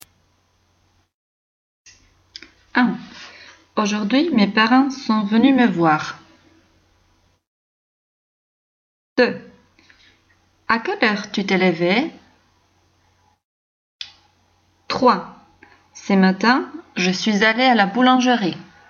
Dictée 1